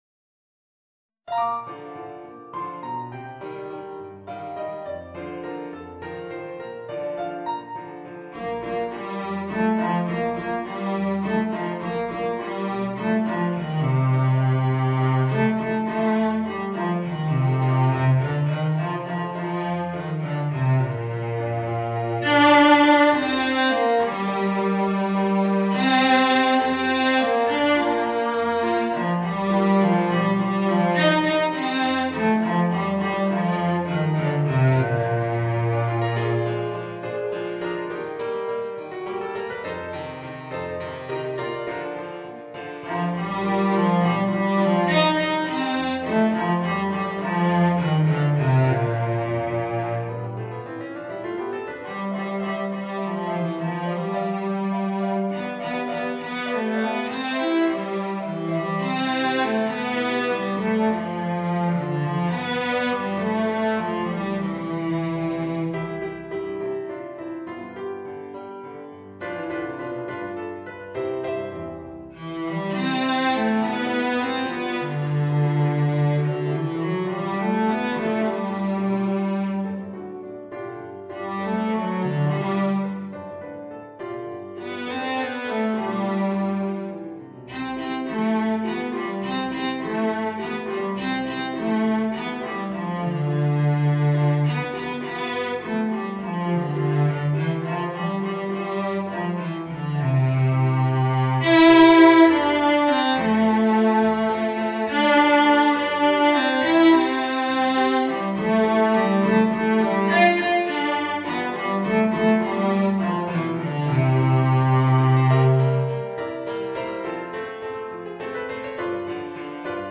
Violoncello and Piano